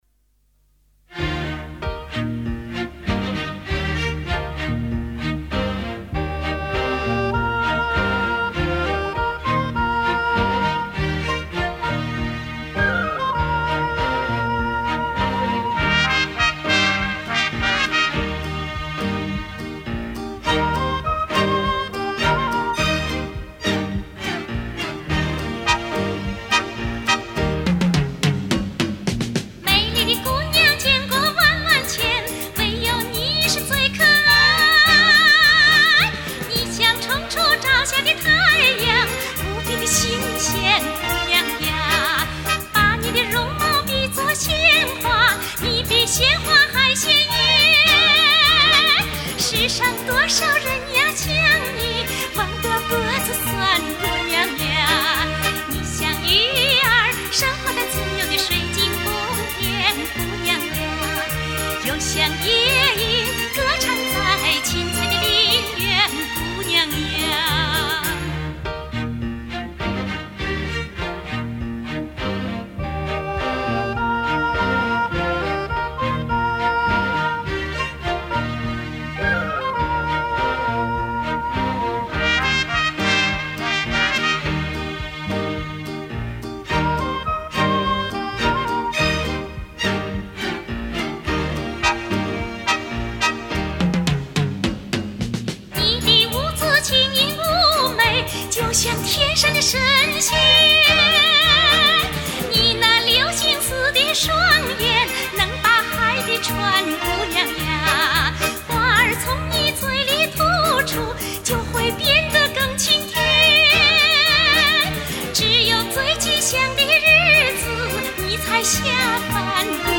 名人独唱专辑